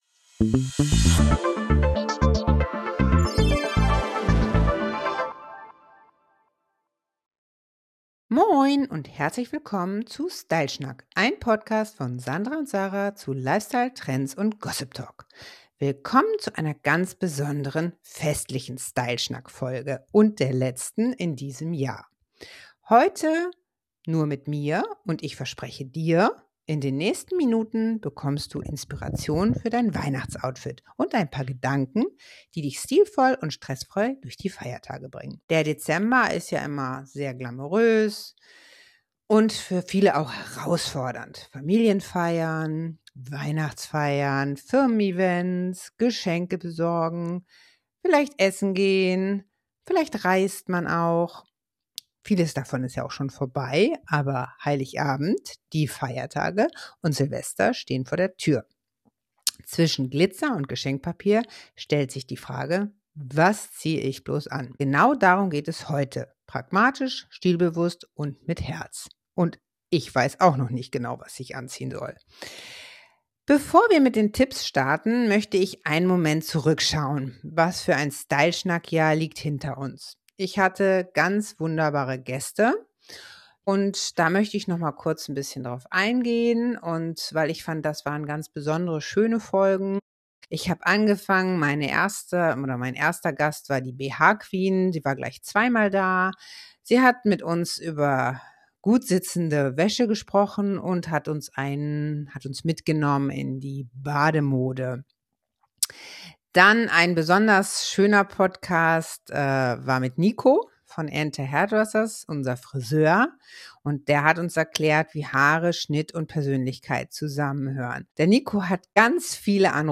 In dieser festlichen Solo-Folge nehme ich Dich mit in einen